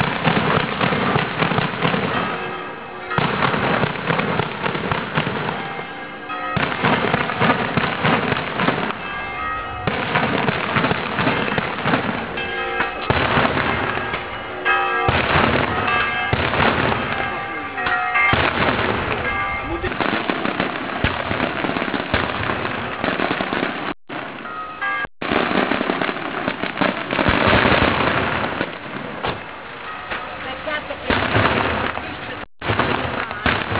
Procession de San Felice
Wave ou RealMedia ) sont organisées: ce sont des lancés de gros pétards, sorte de feux d'artifice en plein jour mais qui consiste à réaliser des séries d'explosions de plus en plus assourdissantes.
petarade_test.wav